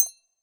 Count Prize (Single Tick).wav